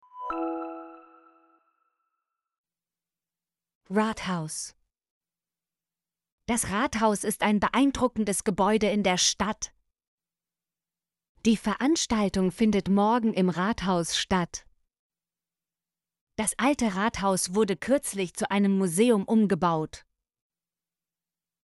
rathaus - Example Sentences & Pronunciation, German Frequency List